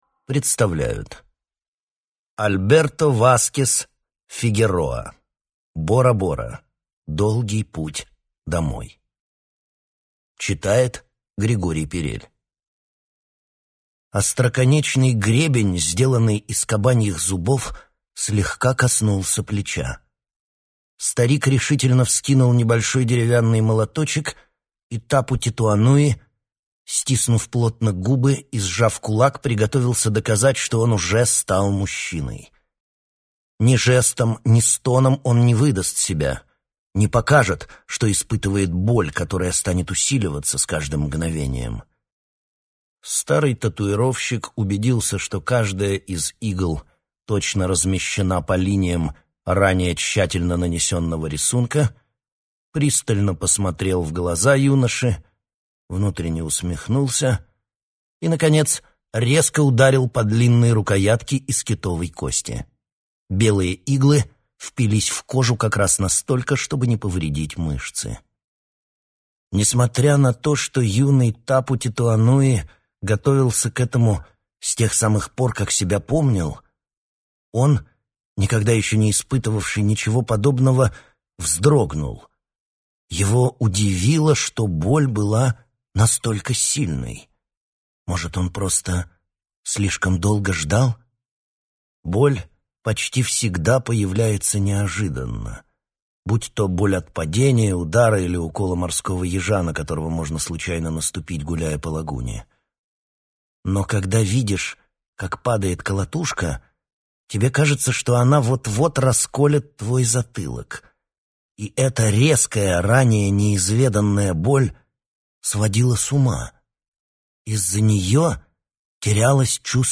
Аудиокнига Бора-Бора. Долгий путь домой | Библиотека аудиокниг